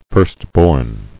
[first·born]